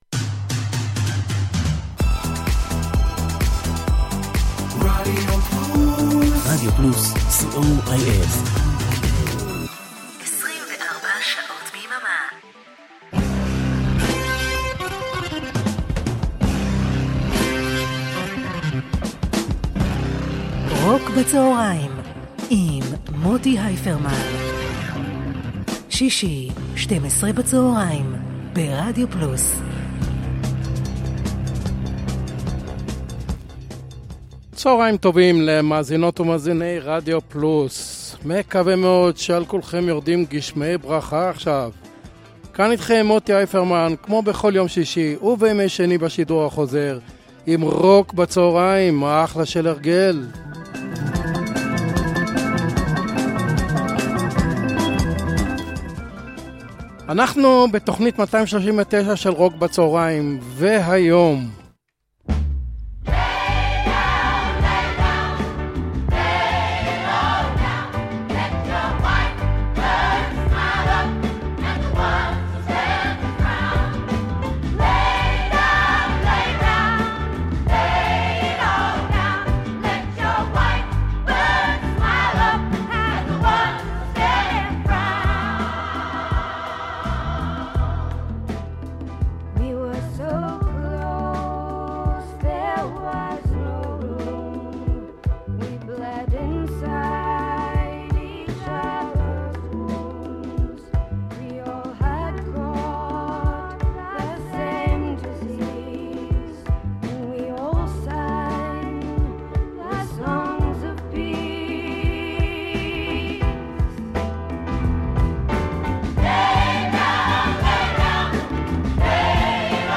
classic rock
pop rock